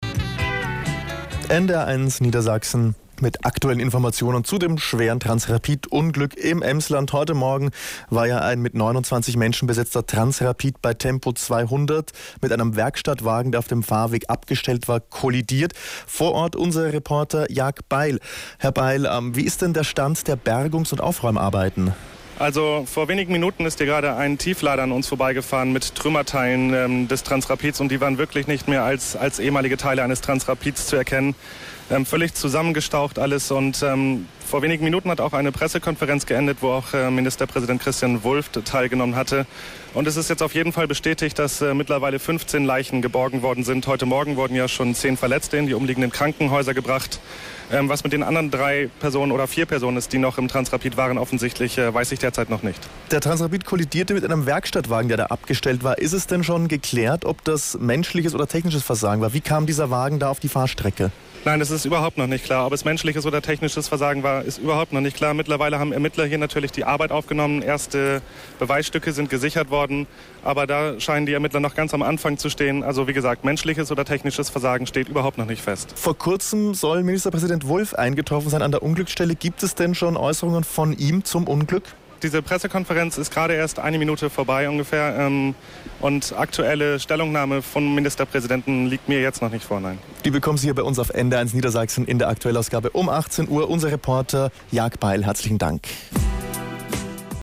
Hörprobe Liveschalte
transrapidschalte.mp3